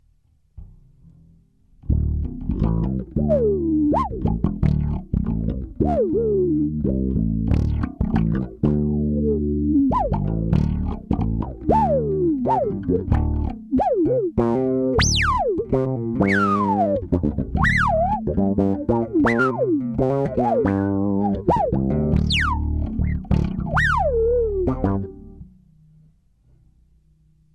Kdybych používal všechny jeho možnosti tak to asi neřešim, ale já si nejčastěji hraju hlavně s parametrem resonance a trochu freq, s expresion pedalem se to dá krásně dostat do zpětnovazební oscilace, takže to pak krásně píská, kníká apod. a to je hlavní důvod proč ho mám.
Udělal jsem takovou rychlou demonstrativní nahrávku pro představu o co mi přibližně jde
Moog kvikani